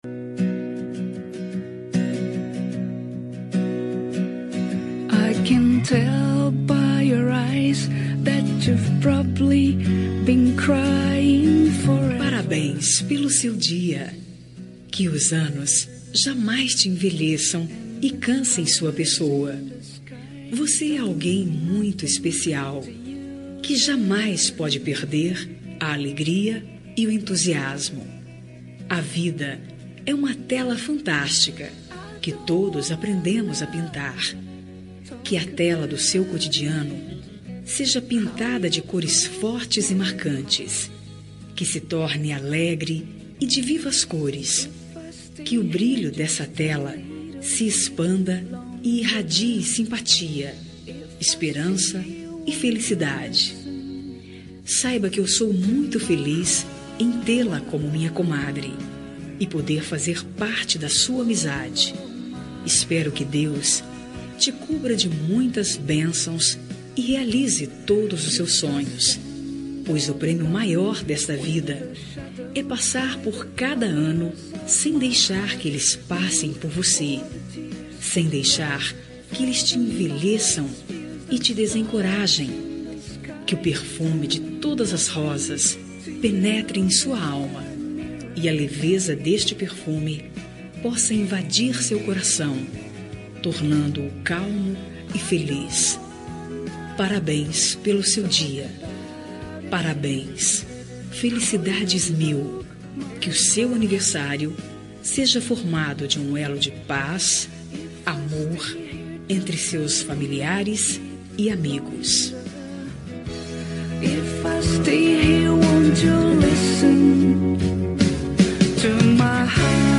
Aniversário de Comadre – Voz Feminina – Cód: 202144